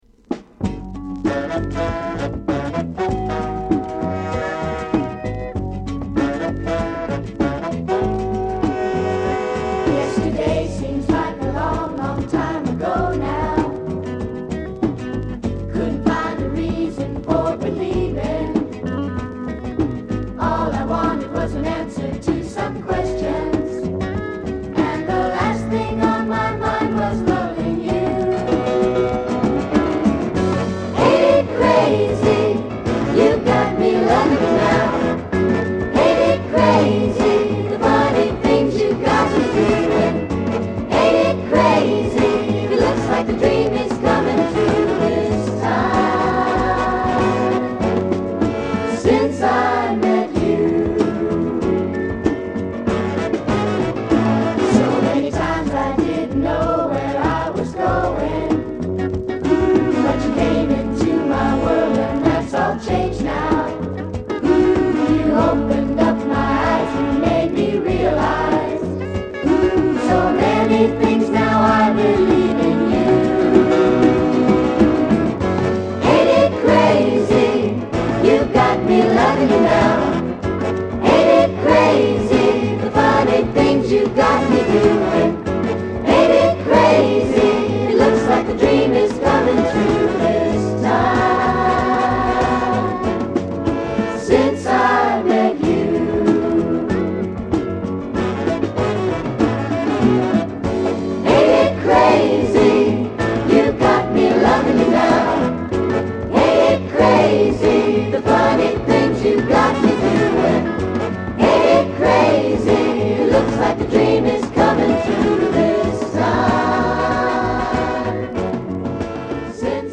KIDS SOFT ROCKコーラスの大傑作！大人気キッズソウル